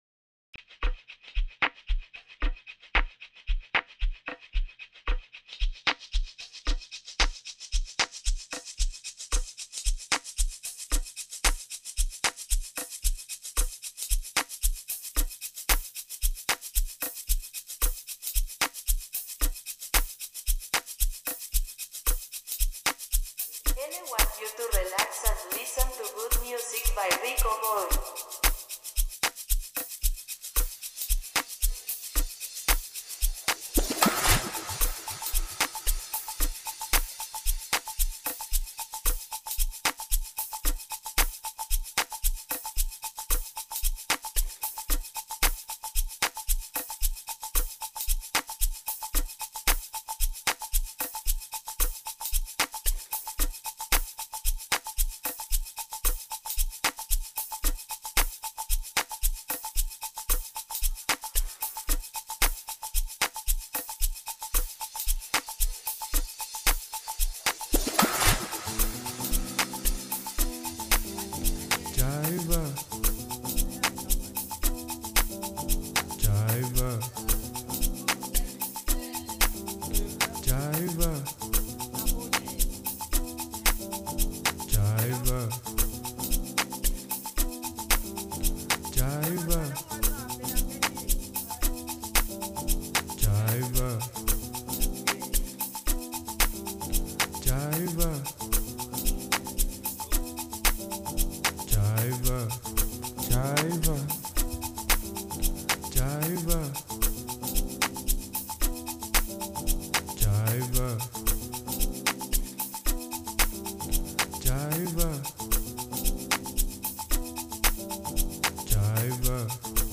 46:00 Genre : Amapiano Size